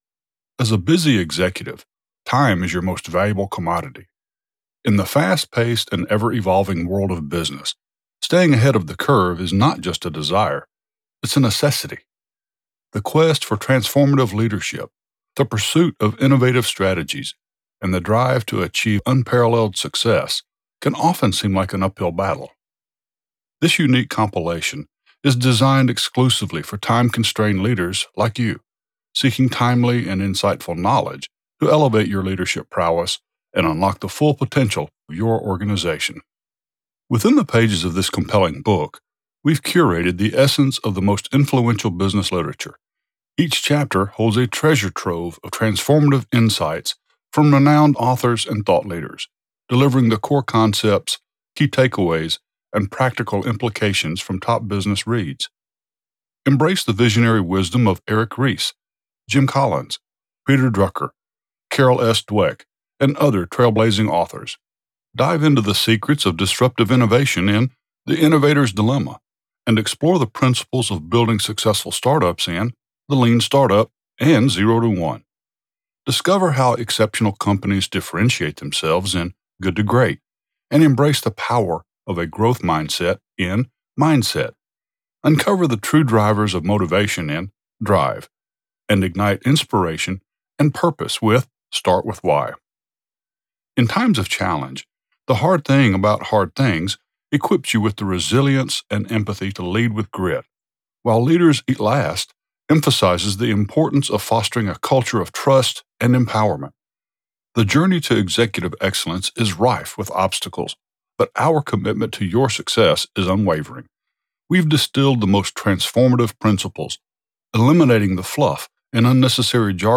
I am a professionally trained voice talent with a mature, slight southern accent.
Audiobook - Non-Fiction - Business Self-help
SAMPLE - Audiobook - 3rd Person - Business Nonfiction.mp3